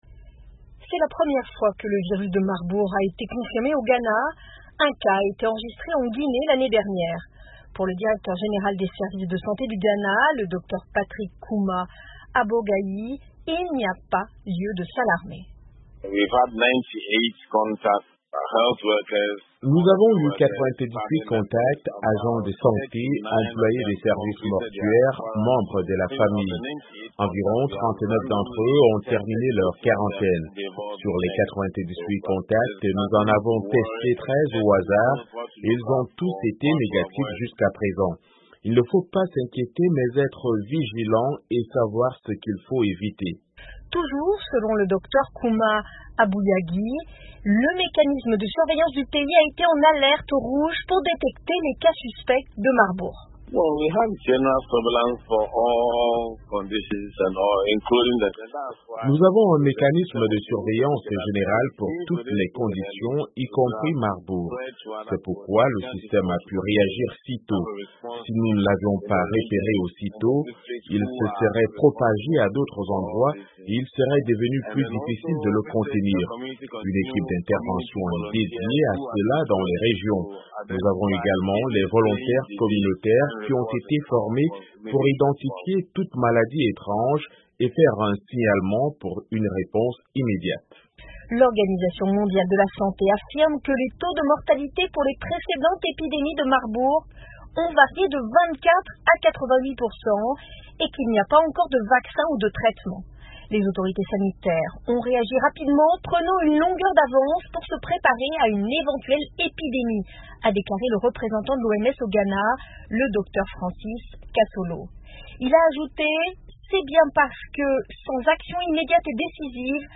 Reportages VOA